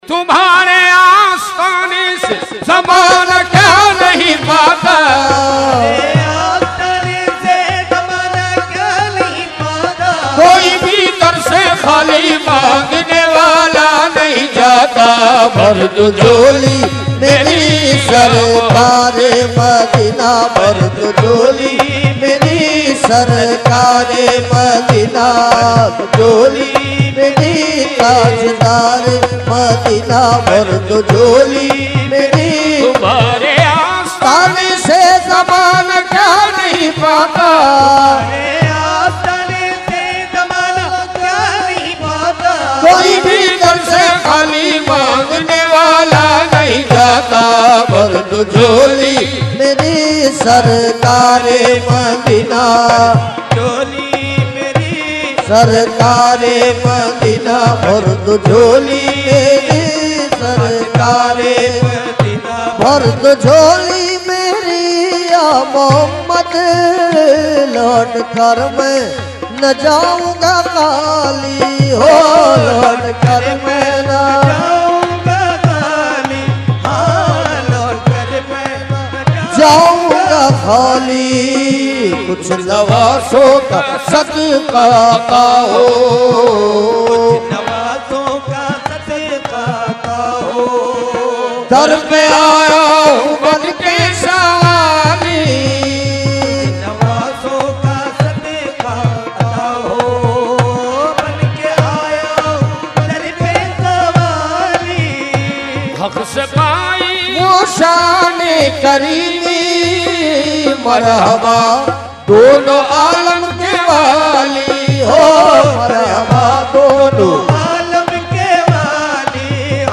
Mediaa: Urs Makhdoome Samnani 2012
Category : Qawali | Language : UrduEvent : Urs Makhdoome Samnani 2012